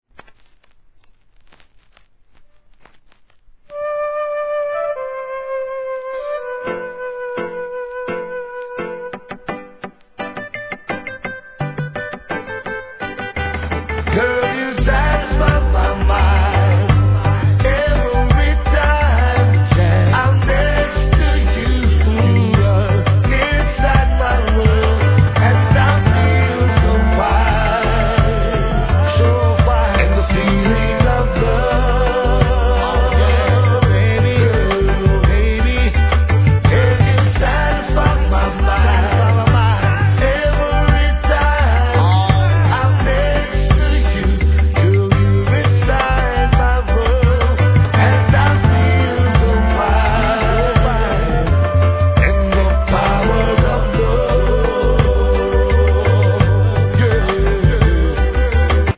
REGGAE
濃圧ベースにさすがのヴォーカル!!